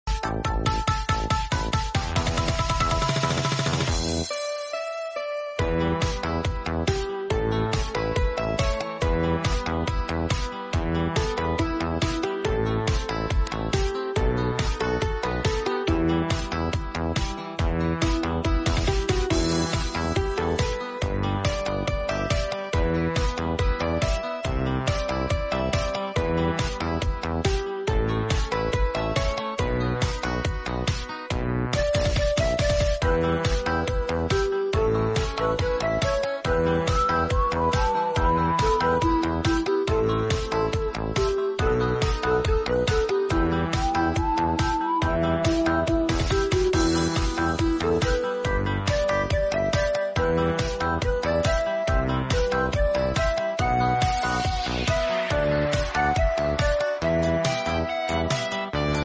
nhạc chế / Remix
Nhạc Chế Vui Vẻ Xả Stress